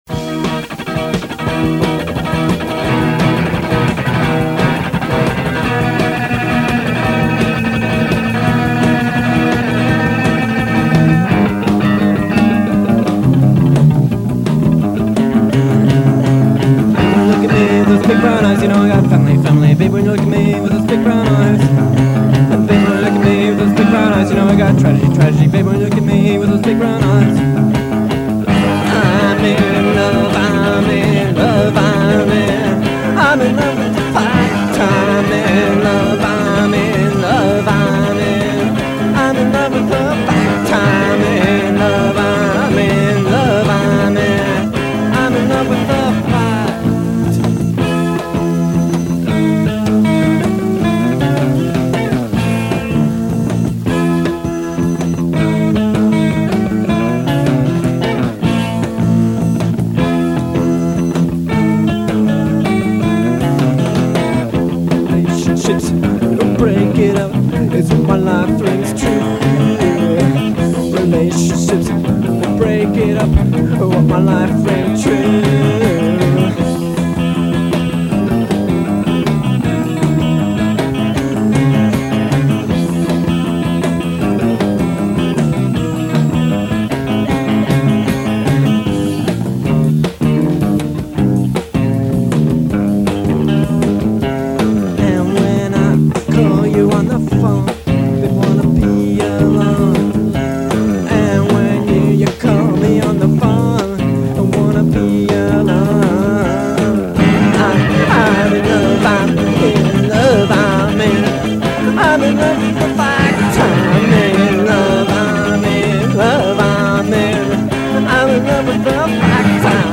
drums
guitar & bass
guitar & vocals
keyboards
"post-punk" band